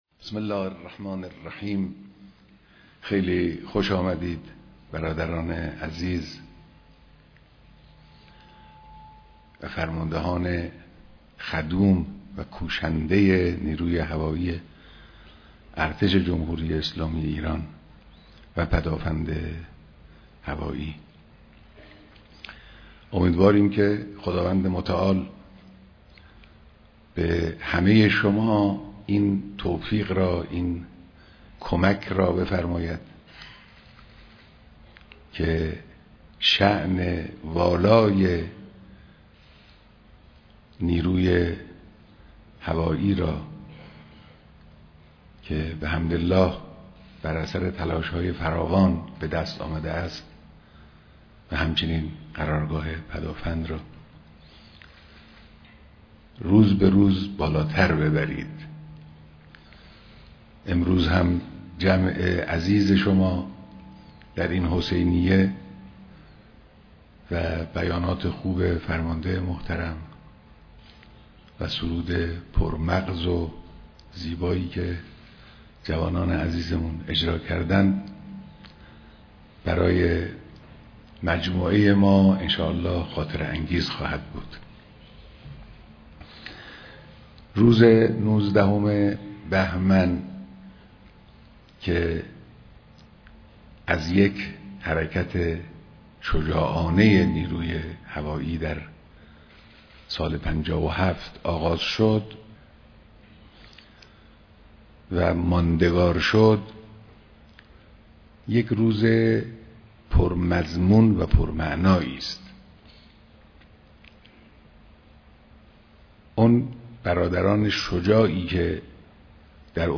بيانات در ديدار فرماندهان وخلبانان نيروى هوائى ارتش